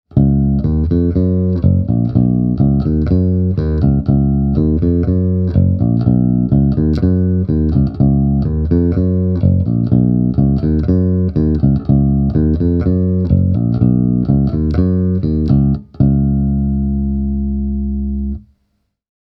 This 40-watter comes loaded with a 10-inch speaker and a two-inch treble horn.
The Warwick BC 40 packs a surprising amount of punch for such a small bass amp.
But it’s not only the volume – this little combo also sounds much larger than it actually is.
The following soundbites have been recorded using a bass with a MM-type humbucker and passive electronics: